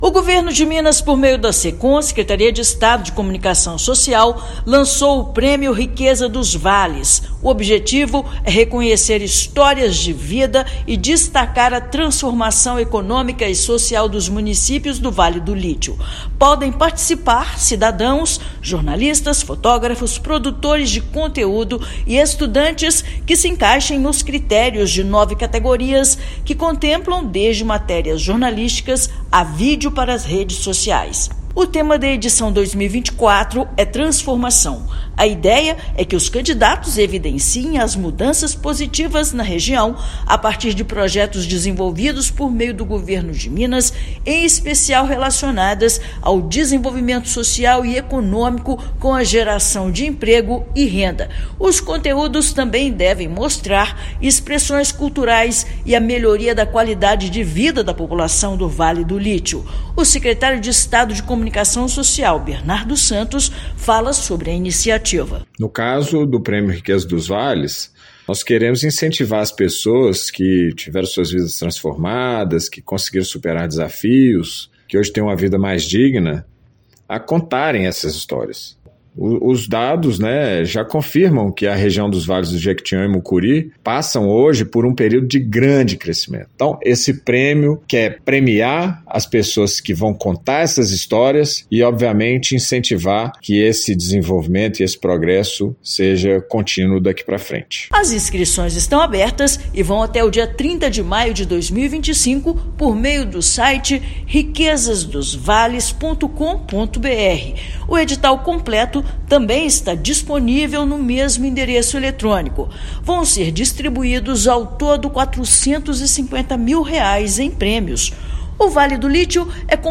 Com inscrições abertas, concurso Riquezas dos Vales vai premiar conteúdo jornalístico, de redes sociais e outras categorias que destaquem a transformação na realidade dos municípios da região. Ouça matéria de rádio.